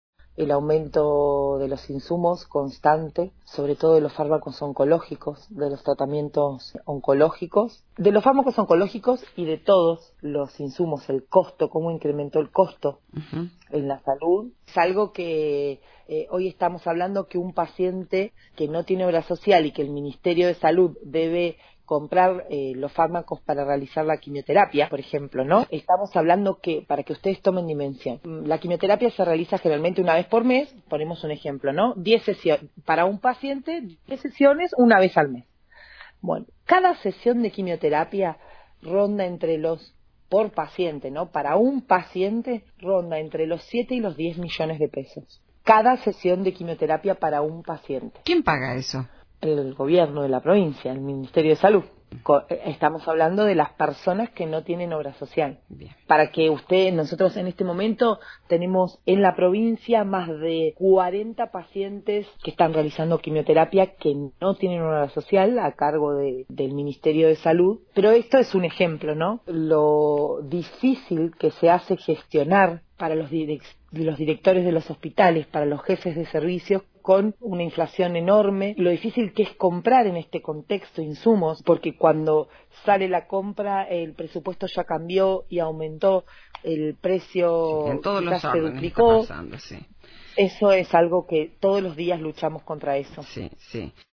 En una entrevista para ((La 97)) Radio Fueguina, la ministra de Salud de la provincia, Judith Di Giglio, señaló que hay stock de fármacos, pero la situación es grave en cuanto a los medicamentos oncológicos.